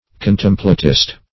Contemplatist \Con*tem"pla*tist\, n. A contemplator.